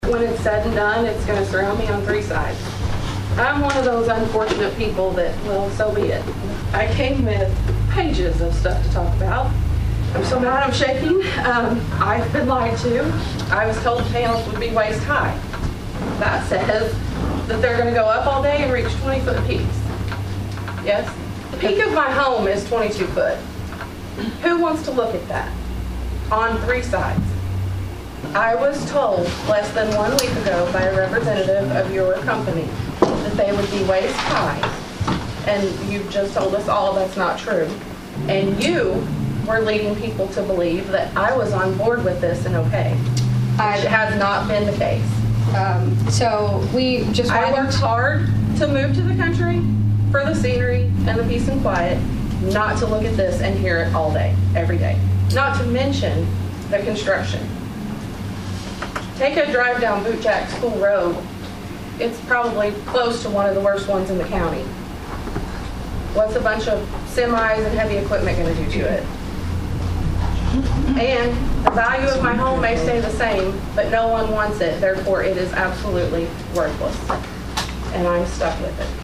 Another White County Board Public Hearing; Another Lively Discussion